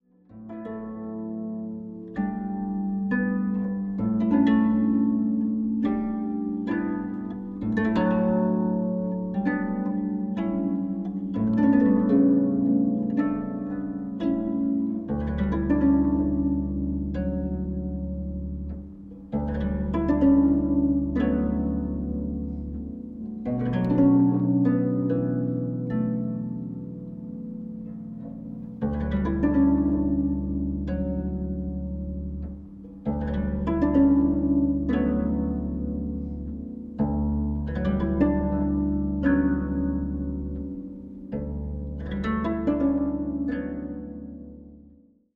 十七絃箏と、カリンバ／ハーモニウム／女声による音の綴り。
中低音の響きに豊かな印象を受ける十七絃箏が持つシンプルさと奥深さ。”
(17-strings koto)